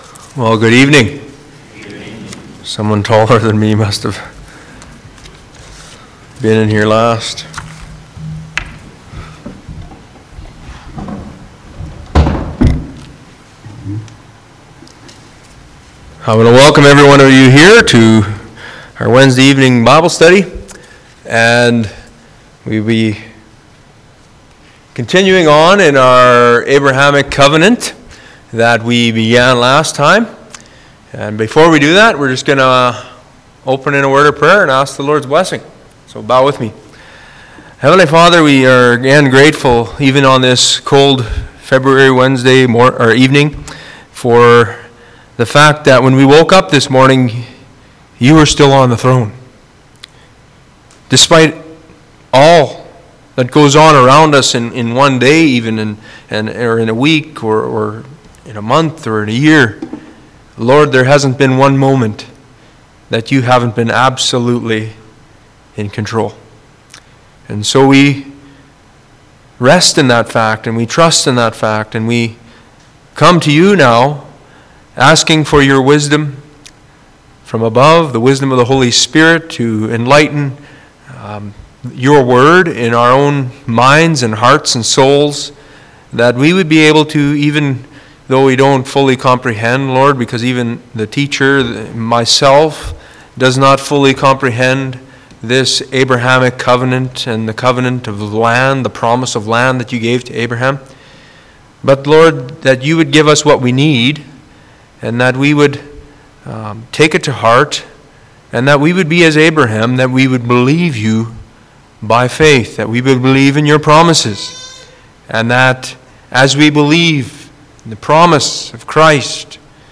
Deut. 30:1-10 Service Type: Church Bible Study « The Bread of Life Church Bible Study